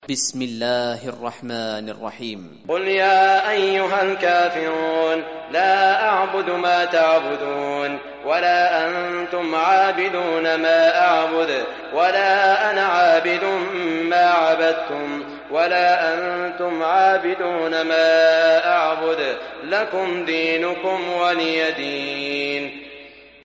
Surah Kafirun Recitation by Sheikh Shuraim
Surah Kafirun, listen or play online mp3 tilawat / recitation in Arabic in the beautiful voice of Sheikh Saud Al Shuraim.